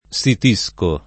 sitire
sitisco [ S it &S ko ]